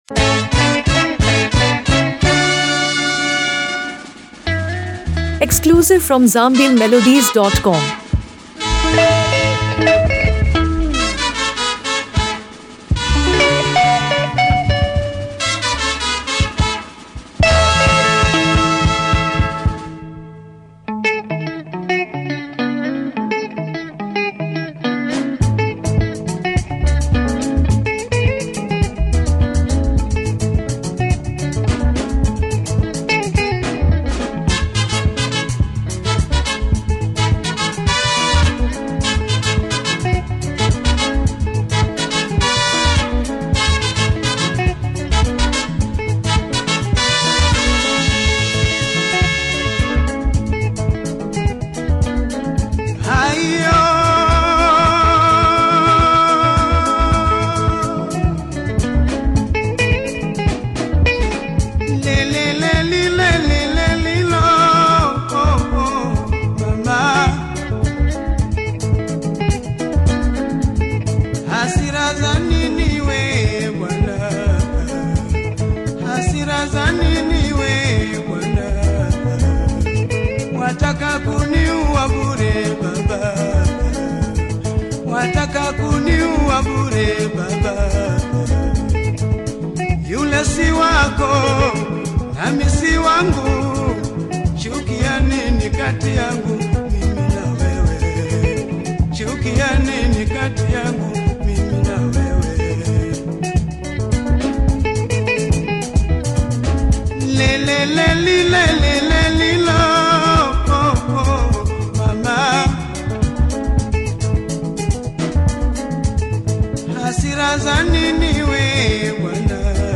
Swahili rumba, Afro-fusion, and soft rock rhythms